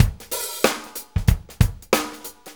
Shuffle Loop 28-03.wav